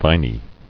[vin·y]